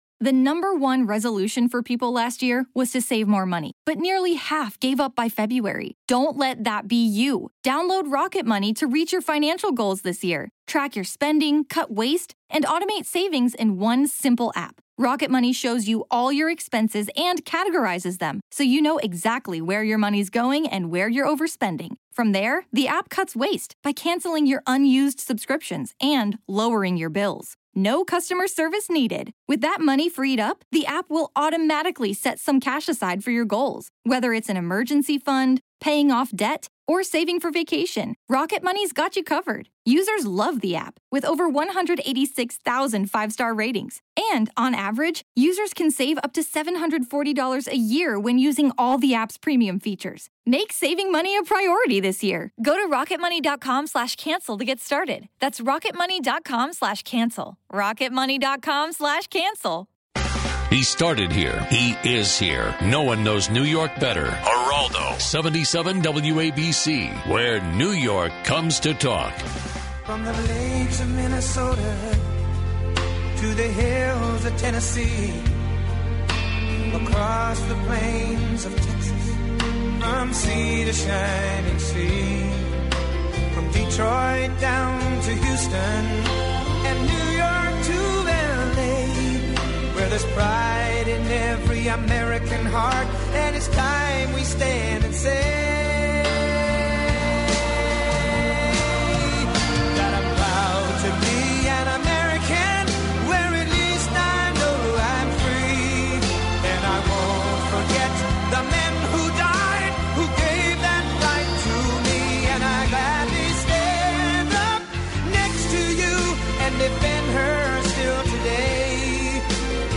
Geraldo covers the latest news & headlines, live and local!